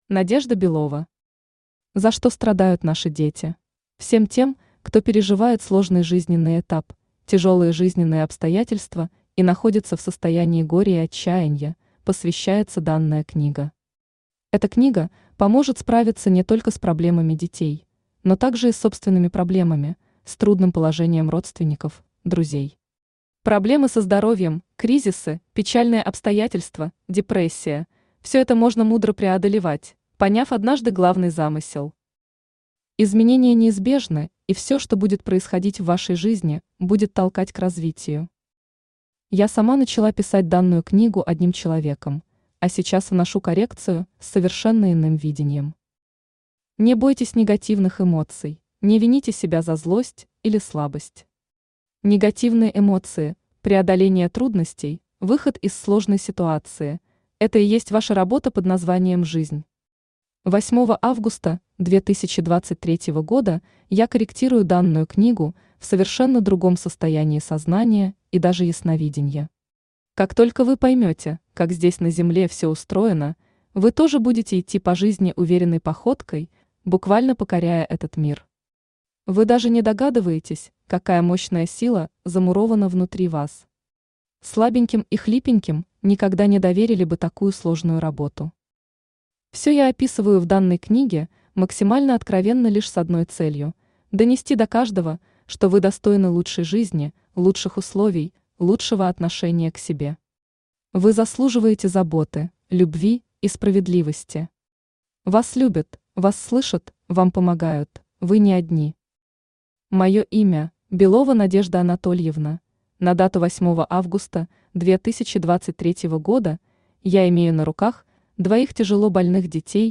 Аудиокнига За что страдают наши дети | Библиотека аудиокниг
Aудиокнига За что страдают наши дети Автор Надежда Белова Читает аудиокнигу Авточтец ЛитРес.